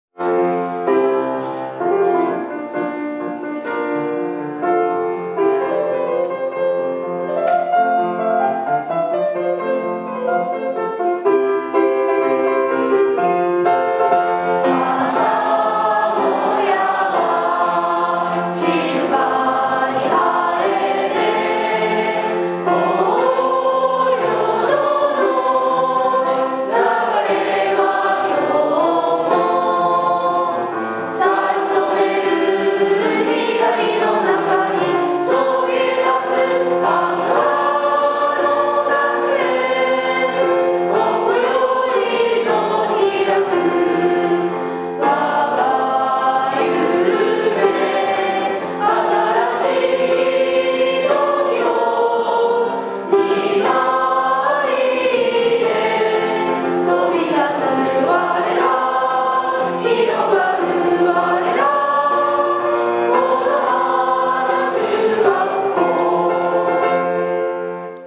本日、平成29年度修了式が行われました。 母校を誇りに思い、校歌斉唱。 その歌声からは、これからの此花中学校を背負っていく 力強さと希望が感じられました。